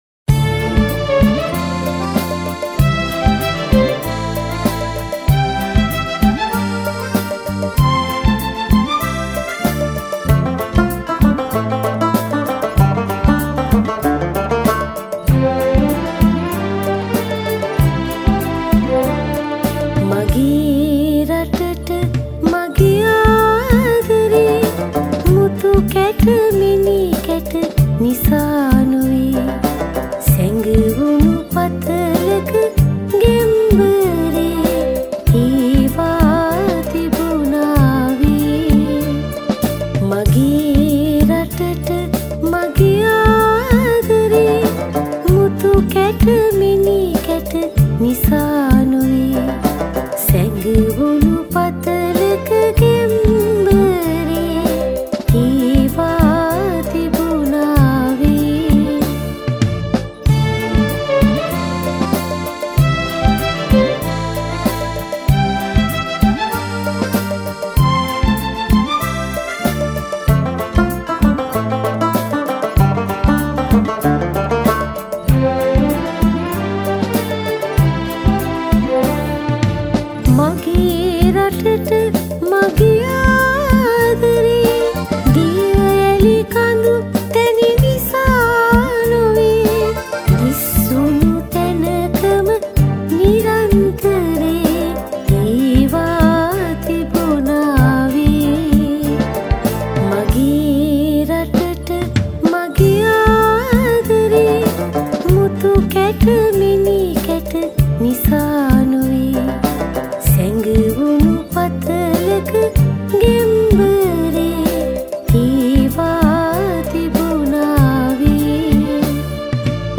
at the Sage Studio, Sri Lanka